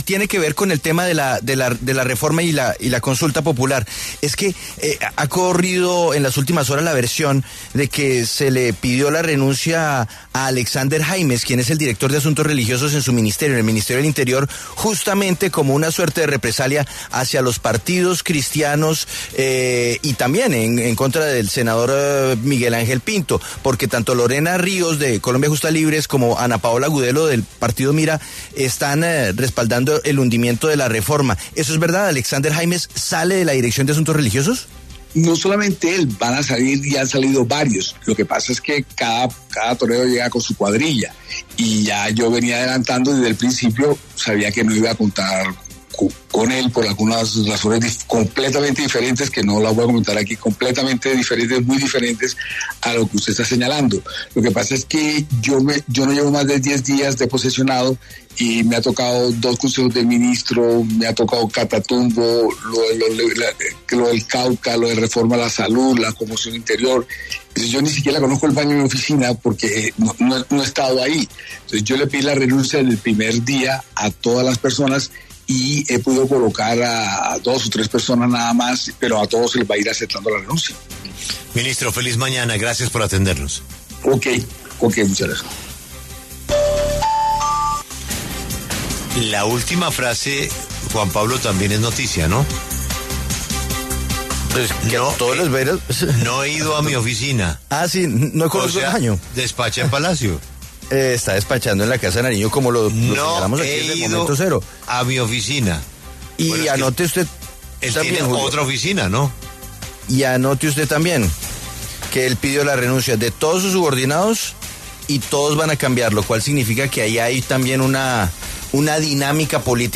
El ministro del Interior, Armando Benedetti, conversó con La W sobre los cambios que tiene planeados al interior de su cartera.
Armando Benedetti, ministro del Interior, habla en La W